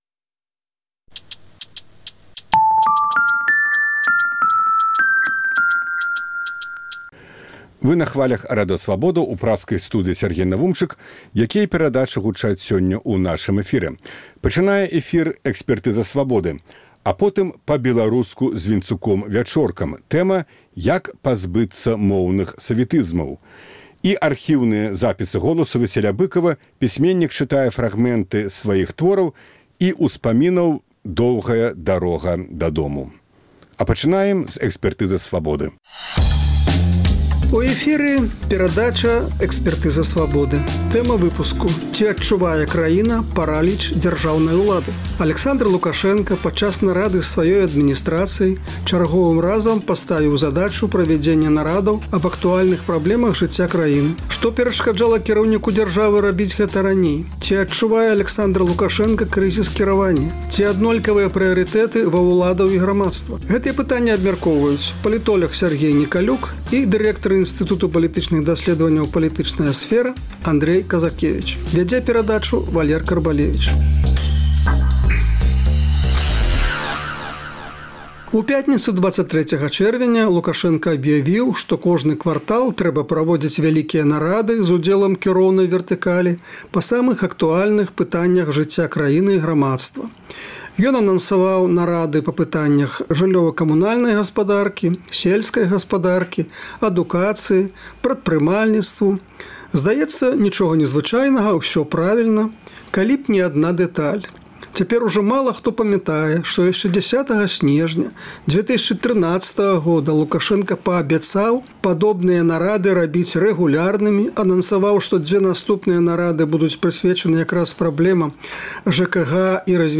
Аналітыкі за круглым сталом